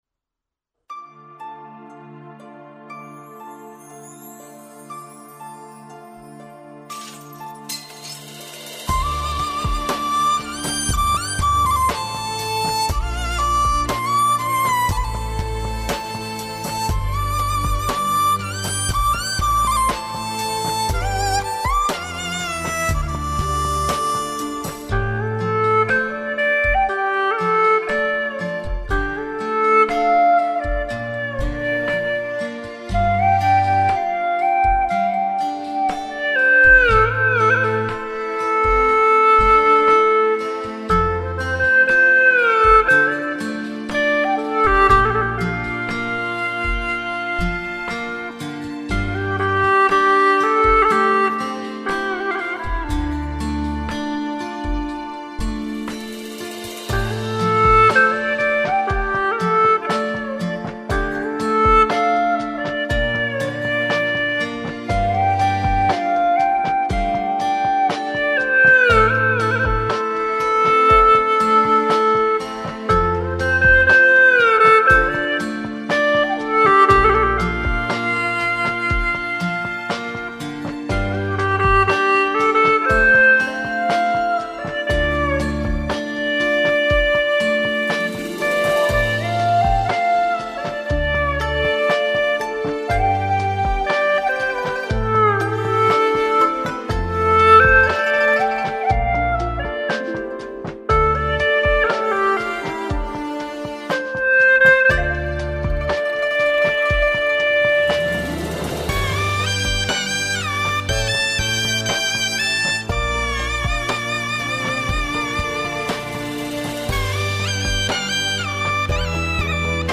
调式 : 降B=1